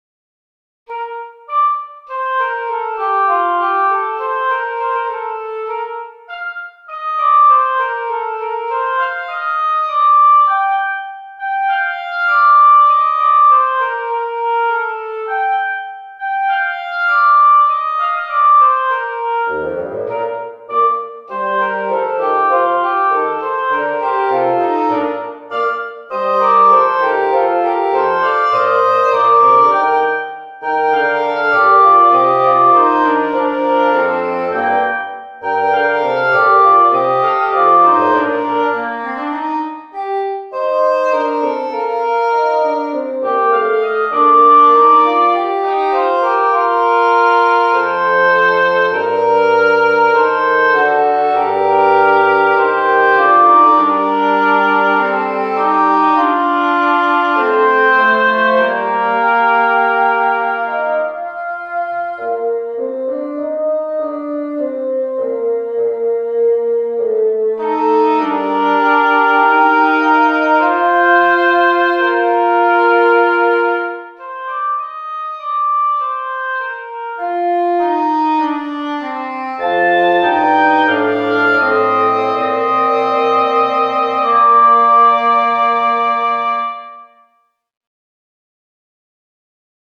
【編成】ダブルリード木管三重奏（Oboe, English Horn, Bassoon）
4楽章からなる小さな組曲です。